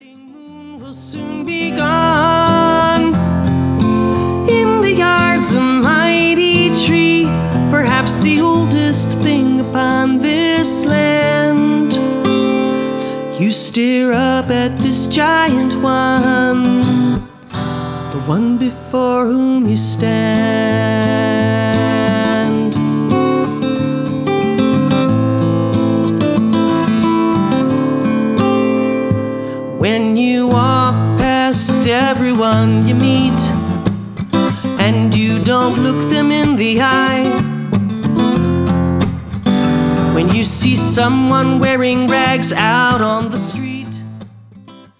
Jewish folk style